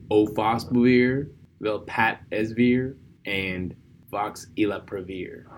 Pronounce: Sofosbuvir / Velpatasvir / Voxilaprevir Brand Name Vosevi